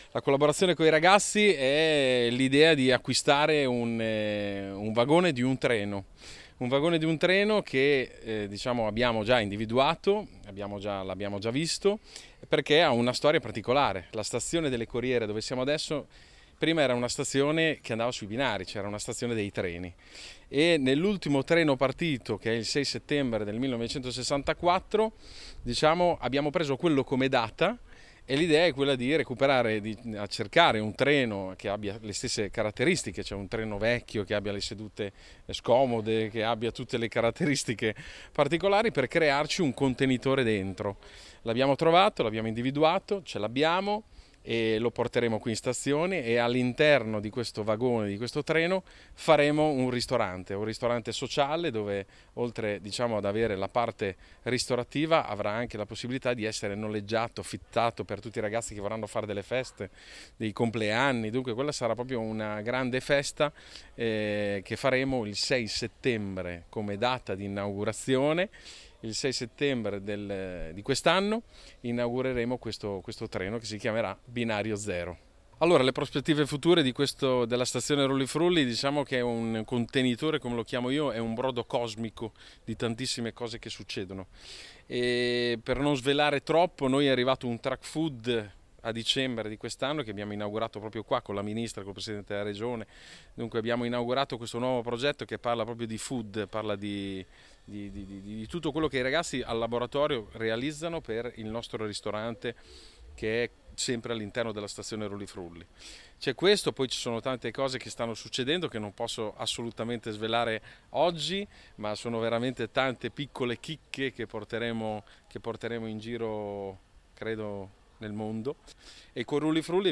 nella giornata di presentazione